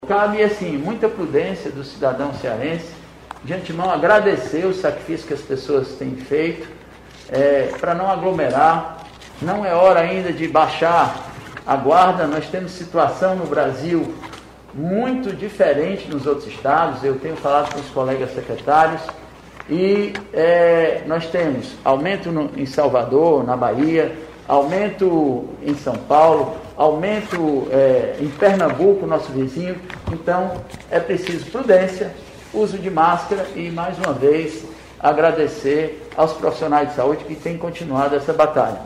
O secretário da Saúde, Dr. Cabeto, alertou que alguns municípios ainda registram taxas elevadas e, por isso, pediu prudência à população cearense.